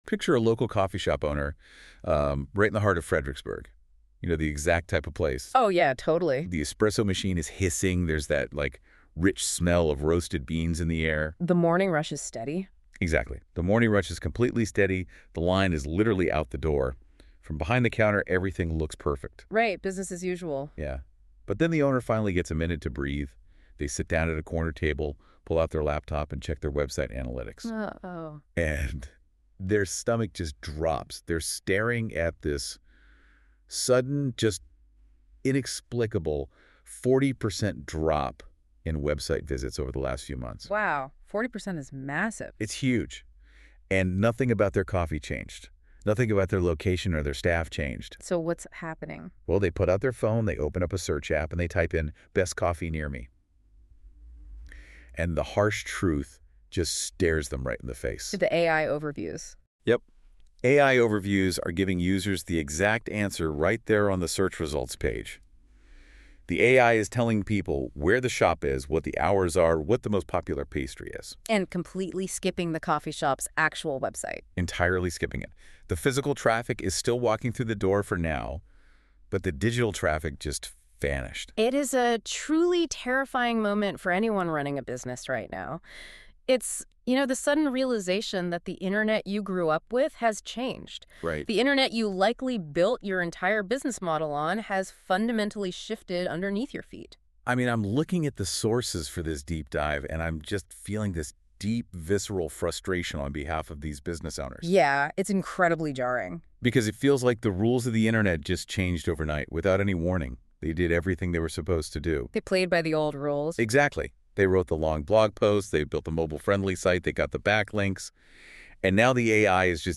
Go Deeper: Listen to the Full Podcast Discussion
Audio generated via Google NotebookLM — sourced from the research and references underlying this post.